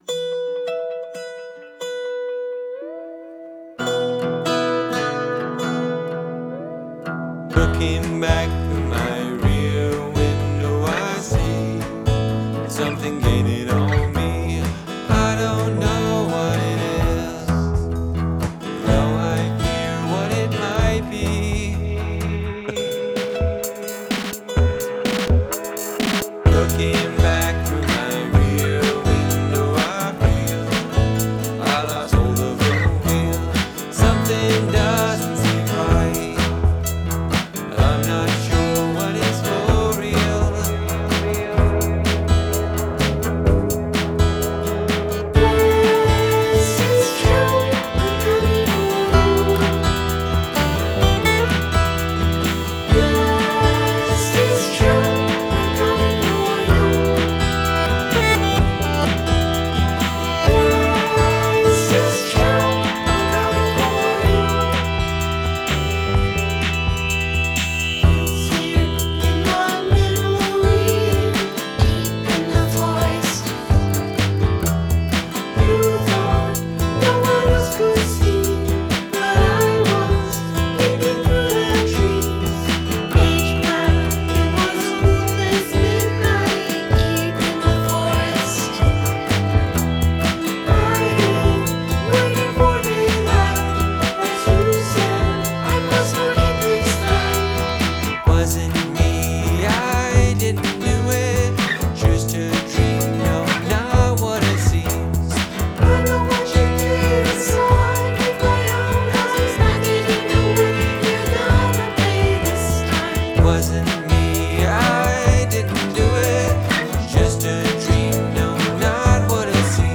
(guest vocal/2nd point of view lyrics in bold italics)